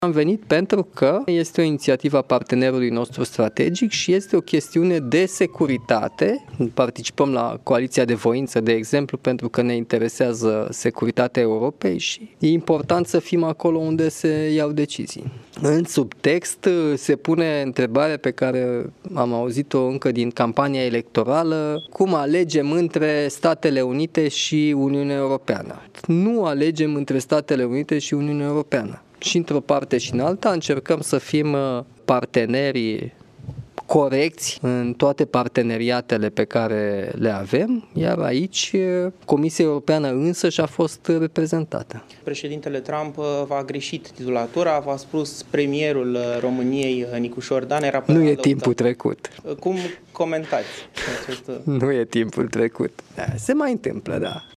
Nu alegem între Statele Unite și Uniunea Europeană, declară președintele Nicușor Dan, după reuniunea Consiliului pentru Pace de la Washington.